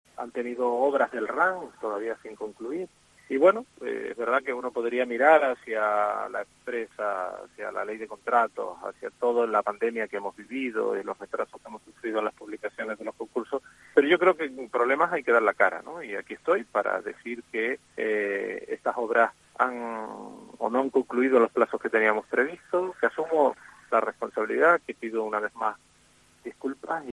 Manuel Domínguez, alcalde de Los Realejos